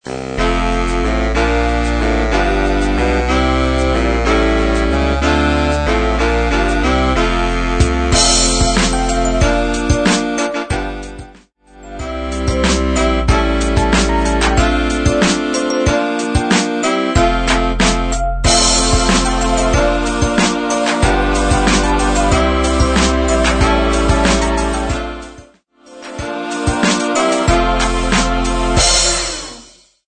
93 BPM
Christmas